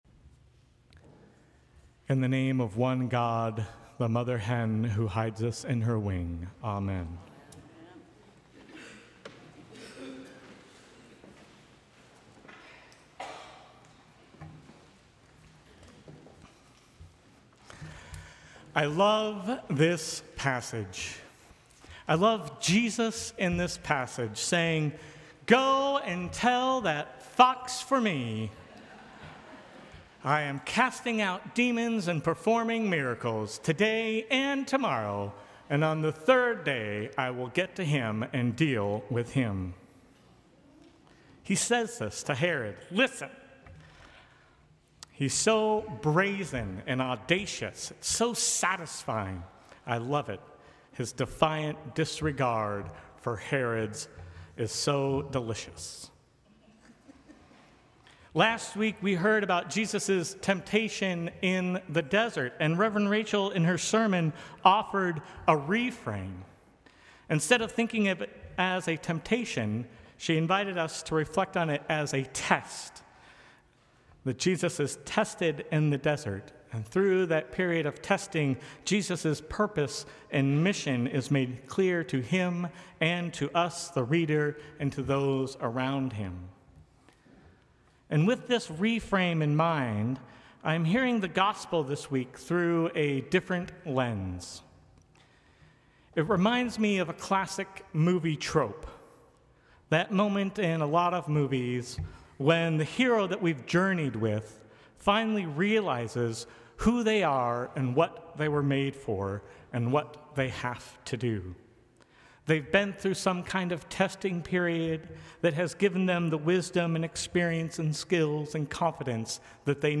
Sermons from St. Cross Episcopal Church Second Sunday in Lent Mar 16 2025 | 00:13:17 1x 00:00 / 00:13:17 Subscribe Share Apple Podcasts Spotify Overcast RSS Feed Share Link Embed